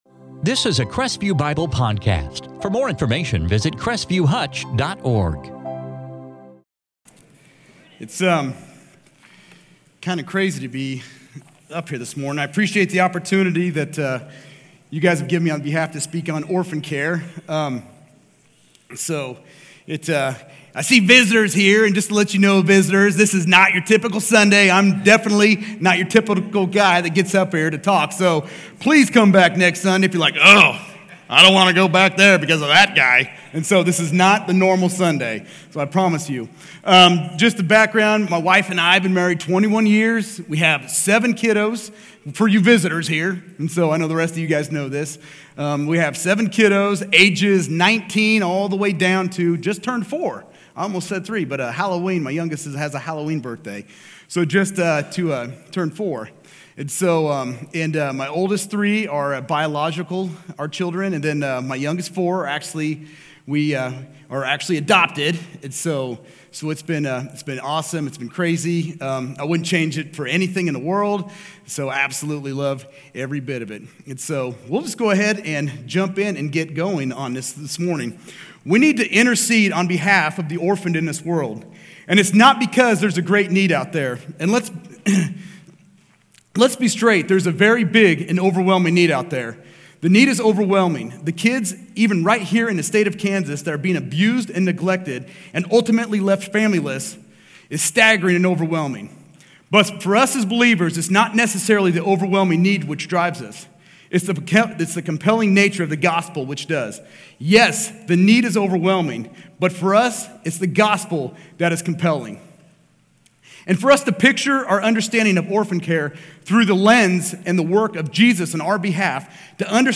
Stand Alone Sermons